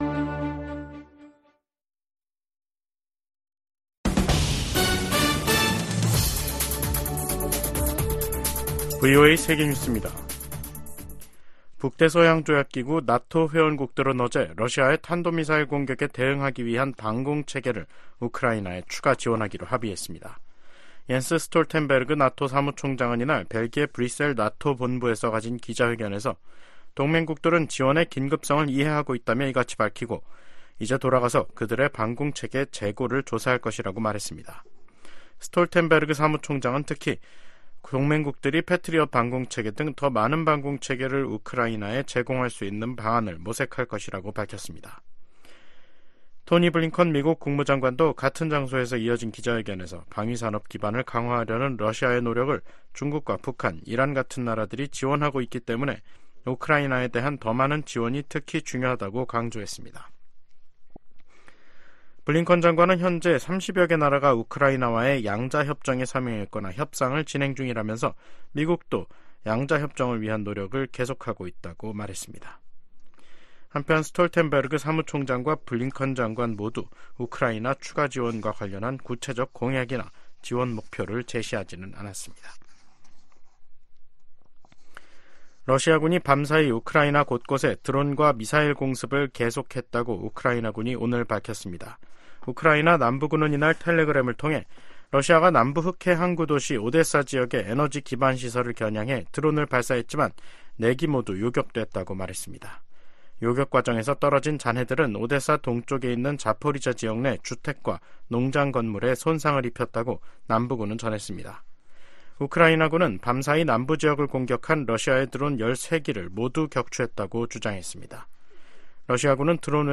VOA 한국어 간판 뉴스 프로그램 '뉴스 투데이', 2024년 4월 5일 2부 방송입니다. 유엔 인권이사회가 올해 20번째로 북한의 심각한 인권 상황을 규탄하는 북한인권결의안을 채택했습니다. 한국과 중국, 일본이 4년여 간 중단됐던 3국 정상회의를 다음달 중 서울에서 개최하는 방안을 협의 중입니다. 유엔 안보리에서 미한일 3국 대표가 북한의 악의적인 사이버 공격에 대한 우려를 표명했습니다.